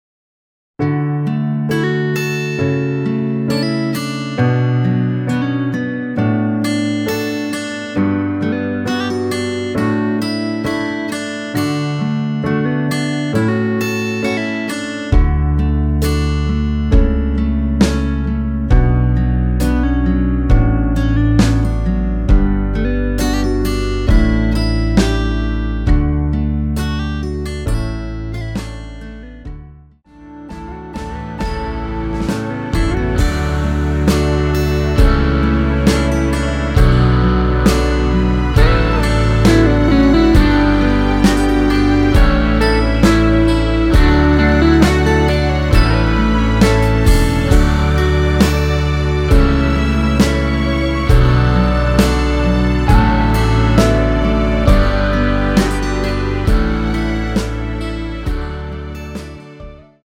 원키에서(+6)올린 MR입니다.(미리듣기 참조)
앞부분30초, 뒷부분30초씩 편집해서 올려 드리고 있습니다.
중간에 음이 끈어지고 다시 나오는 이유는